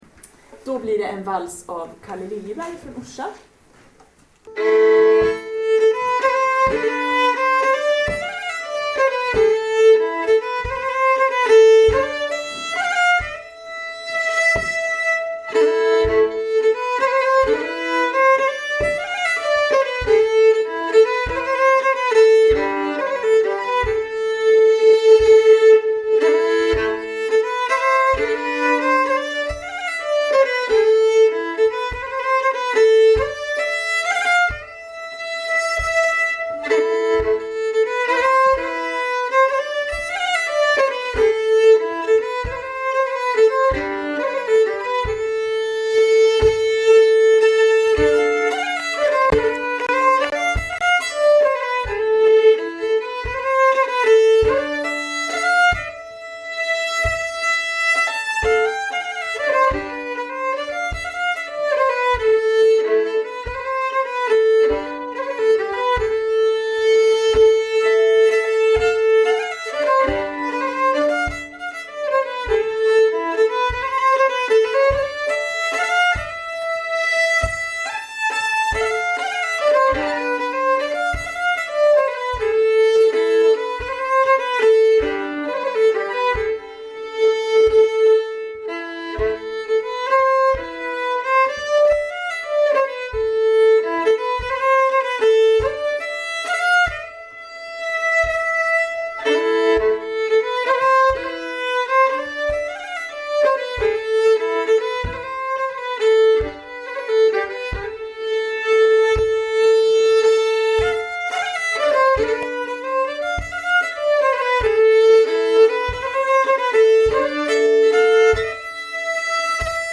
Spelstuga
Vals efter Kalle Liljeberg "normal fart"